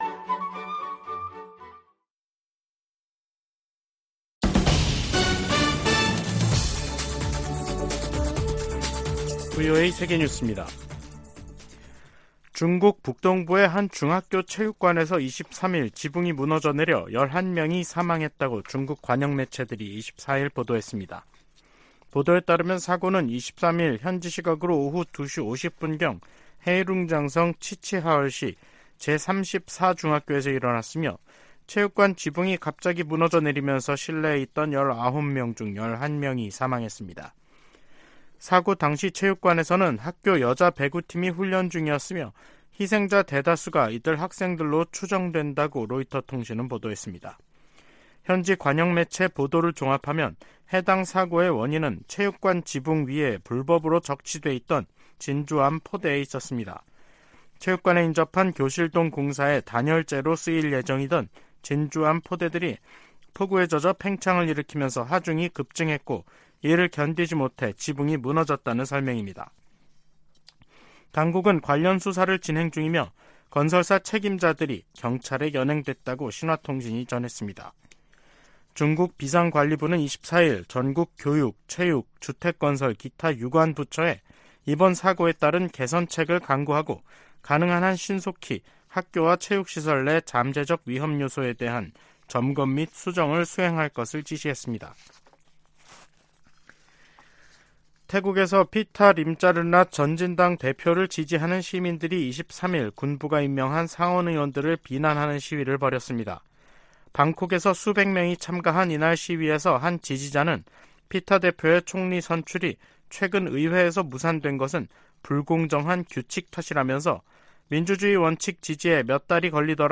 VOA 한국어 간판 뉴스 프로그램 '뉴스 투데이', 2023년 7월 24일 3부 방송입니다. 북한은 지난 19일 동해상으로 단거리 탄도미사일(SRBM) 2발을 발사한데 이어 사흘 만에 다시 서해상으로 순항미사일 수 발을 발사했습니다. 미국은 전략핵잠수함의 부산 기항이 핵무기 사용 조건에 해당된다는 북한의 위협을 가볍게 여기지 않는다고 백악관이 강조했습니다. 유엔군사령부는 월북한 주한미군의 신병과 관련해 북한과의 대화를 시작했다고 공식 확인했습니다.